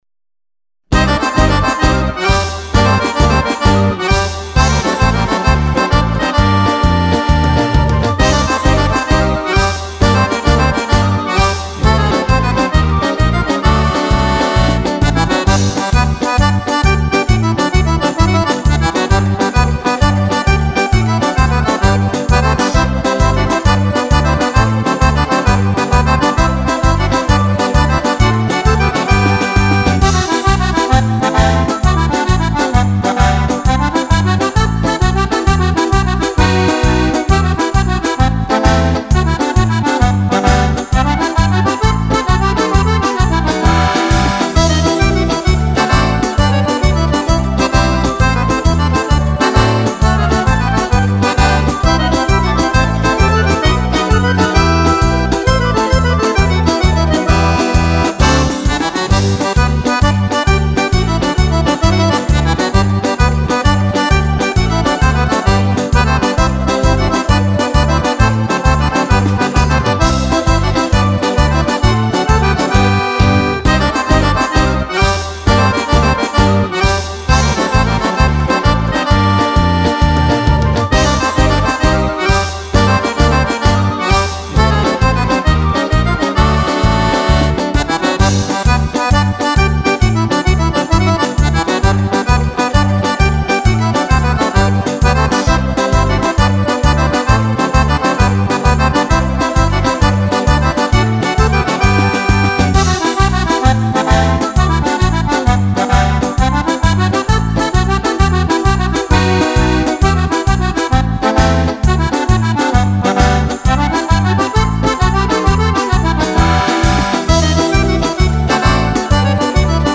Tarantella
10 BALLABILI PER FISARMONICA